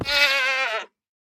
Minecraft Version Minecraft Version snapshot Latest Release | Latest Snapshot snapshot / assets / minecraft / sounds / mob / goat / death1.ogg Compare With Compare With Latest Release | Latest Snapshot